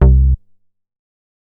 MoogBigUp A.WAV